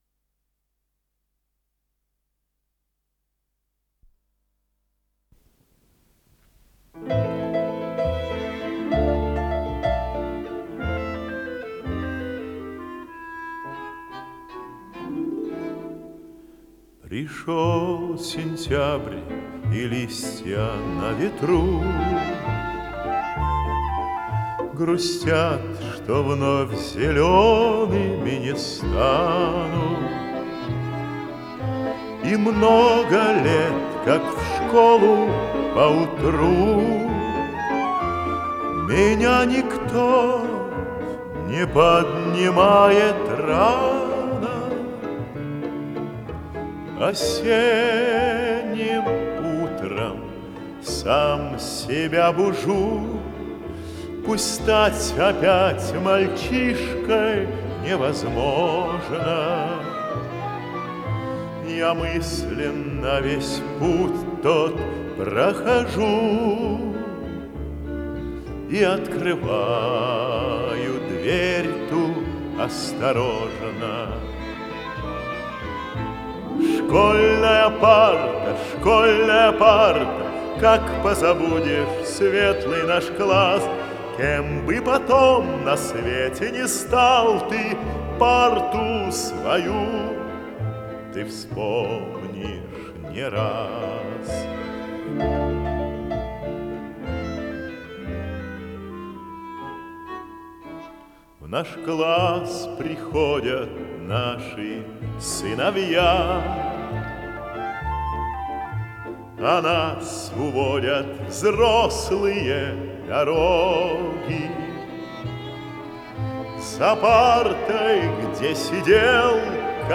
с профессиональной магнитной ленты
баритон
ВариантДубль моно